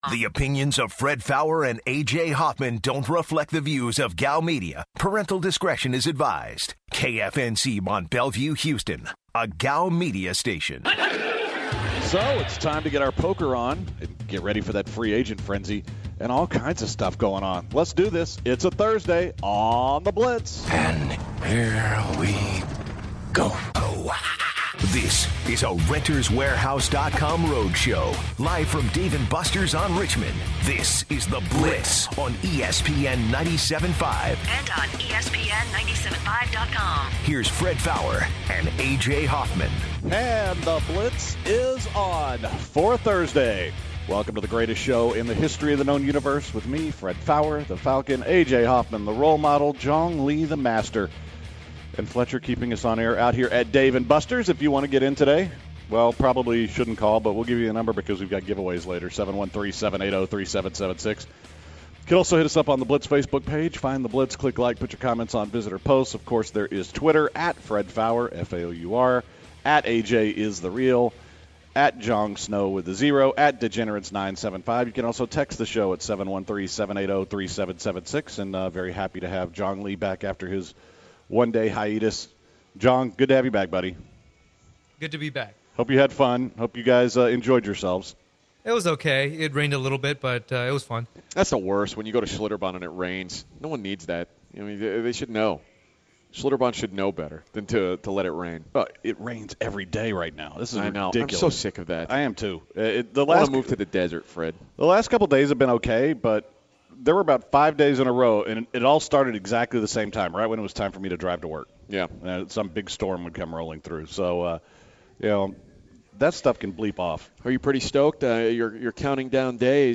This Thursday, The Blitz is live from Dave and Busters at The Blitz Summer Poker Classic.